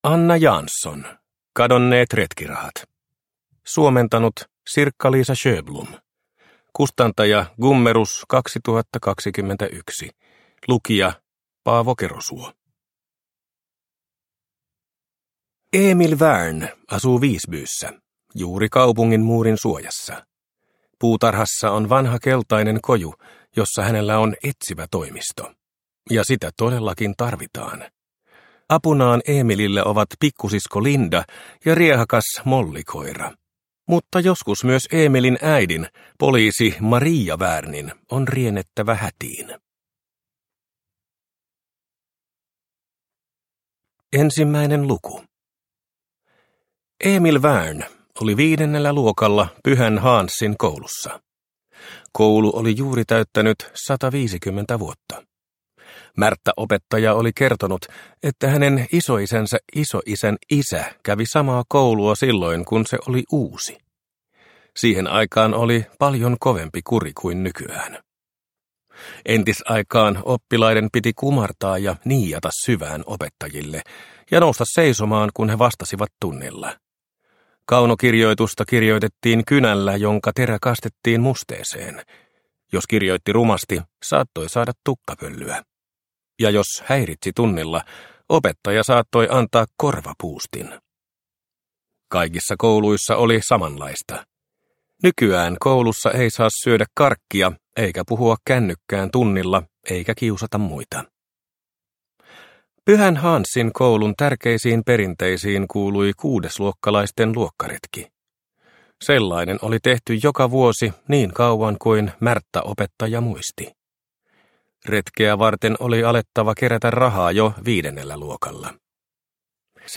Kadonneet retkirahat – Ljudbok – Laddas ner